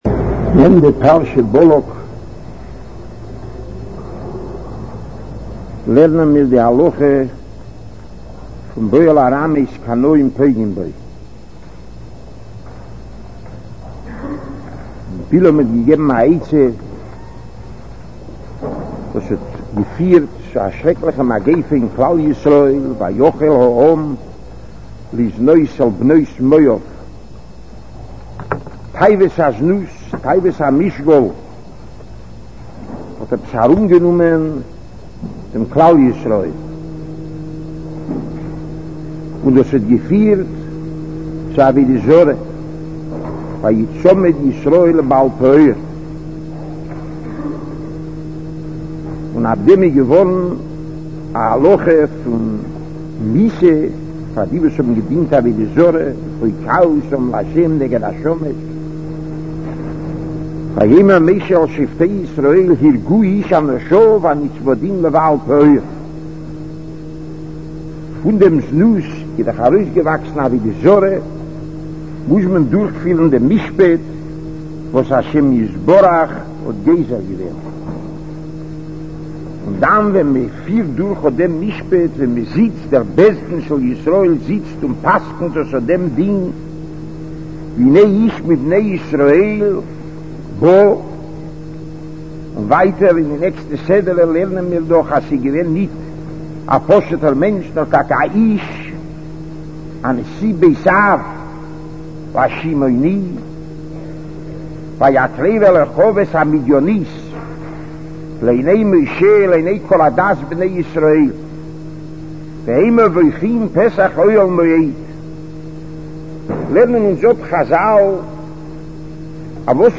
Shiur Daas, Parshas Boluk